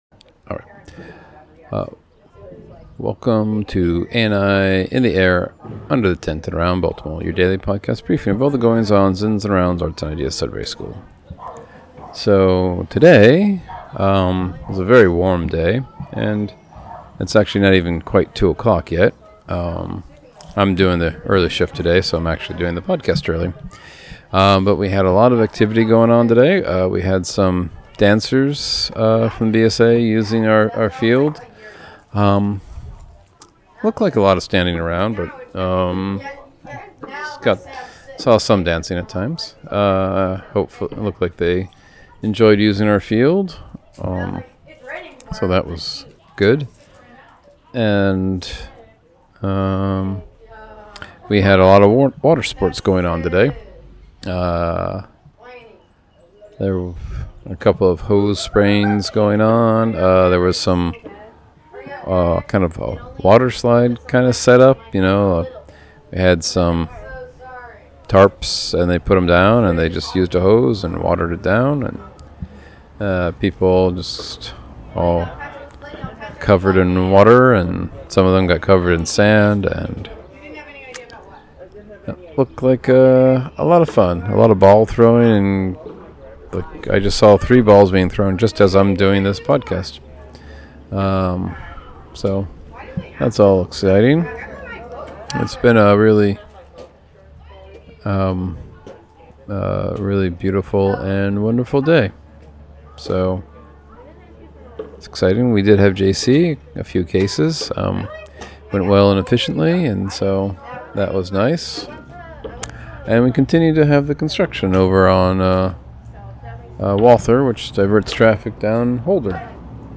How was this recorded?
Early shift, recording before 2pm.